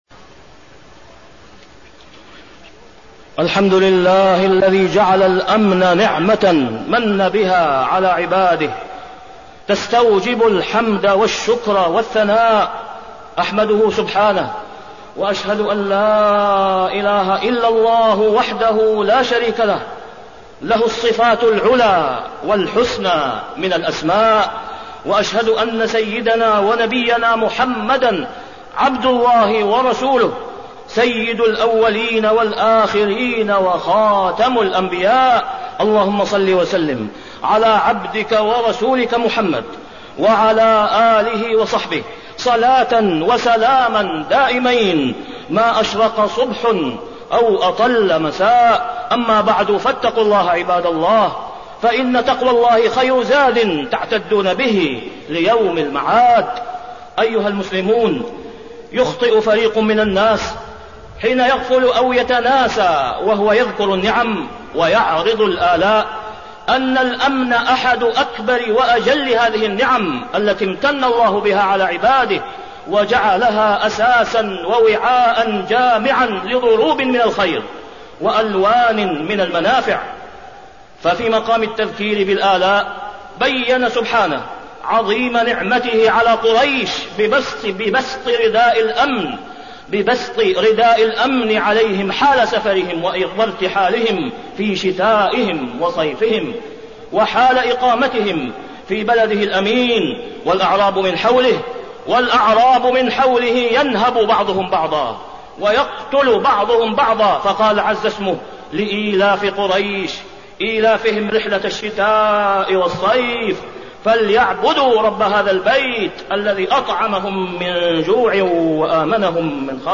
تاريخ النشر ١٣ جمادى الأولى ١٤٣٠ هـ المكان: المسجد الحرام الشيخ: فضيلة الشيخ د. أسامة بن عبدالله خياط فضيلة الشيخ د. أسامة بن عبدالله خياط نعمة الأمن وضرورة المحافظة عليها